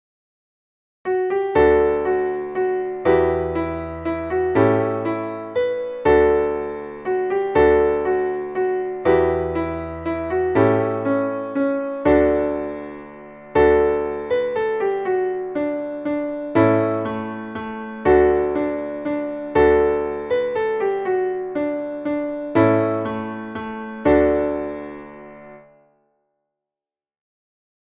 Traditionelles Volkslied